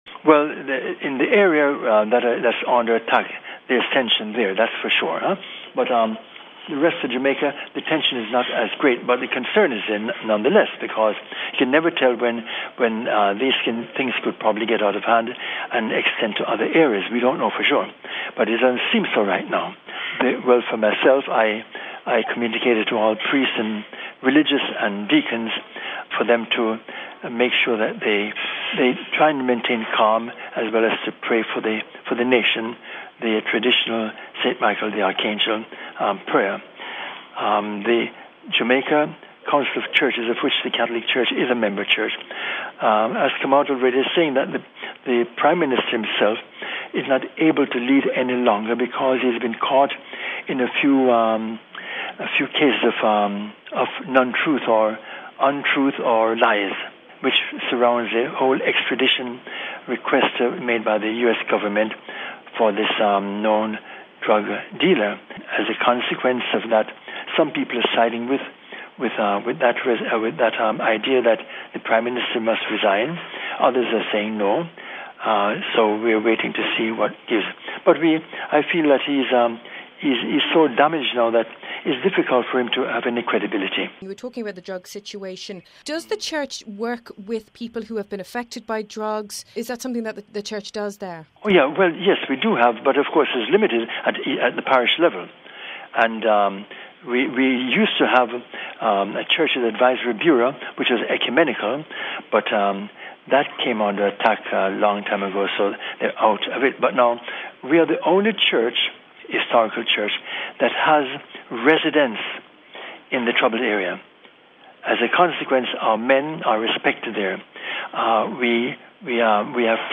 INTERVIEW OF THE DAY
We spoke to Archbishop Donald James Reece of Kingston, about the situation: